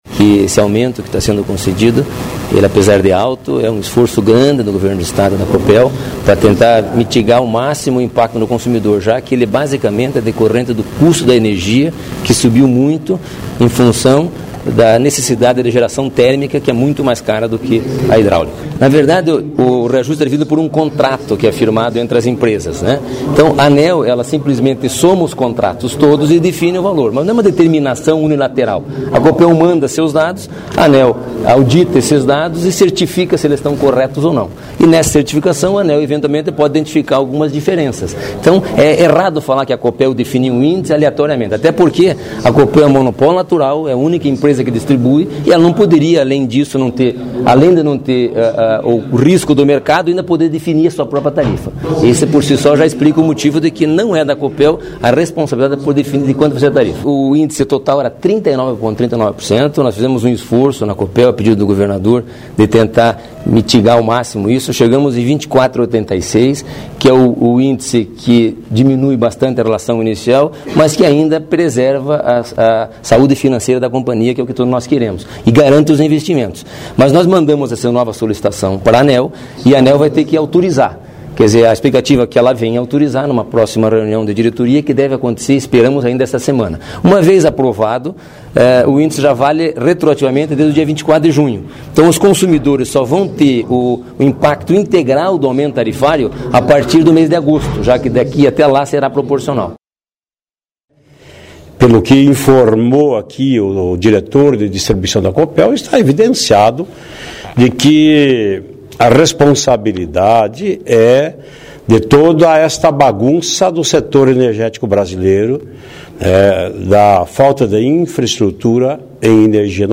Diretores e técnicos da Companhia Paranaense de Energia participaram da sessão desta quarta-feira  da Assembleia Legislativa para prestar esclarecimentos sobre o reajuste tarifário da energia elétrica.//
Confira no arquivo acima as sonoras dele e do líder do Governo na Assembleia, deputado Ademar Traiano.// AS duas sonoras seguem em sequencia: